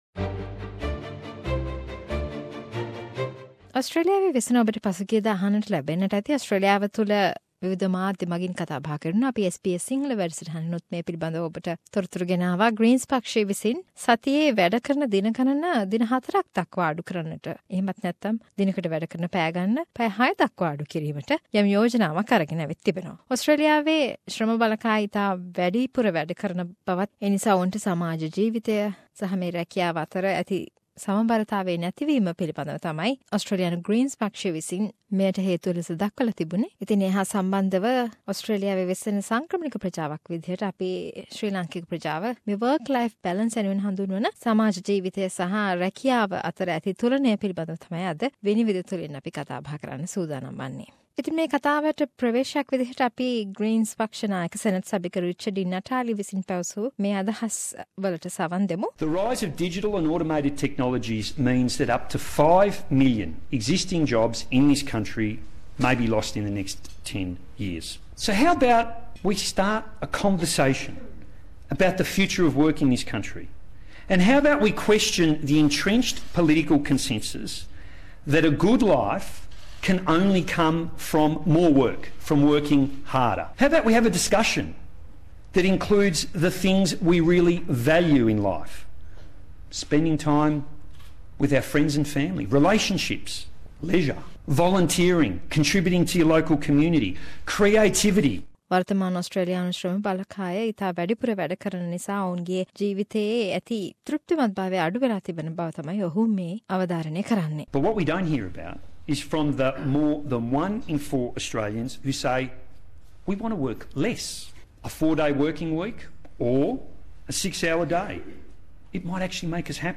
SBS Sinhalese monthly Panel Discussion about Work Life Balance in Australia